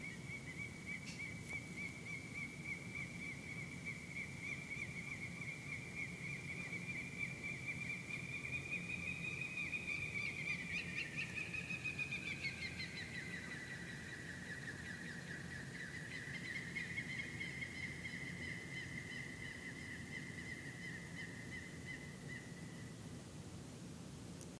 Huerequeque (Hesperoburhinus superciliaris)
Recorded at 3:30 AM at the Lima Cricket and Football Club.
Nombre en inglés: Peruvian Thick-knee
Condición: Silvestre
Certeza: Vocalización Grabada